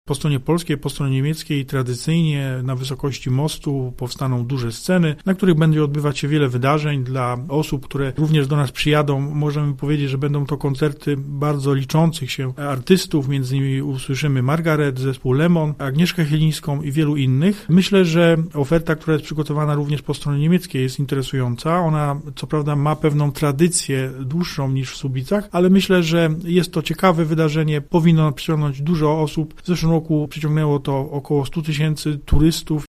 – Mieszkańcy najbardziej czekają na występy muzyczne, ale atrakcji Święta Hanzy będzie więcej – mówi Tomasz Ciszewicz, burmistrz Słubic: